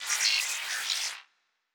Data Calculating 4_4.wav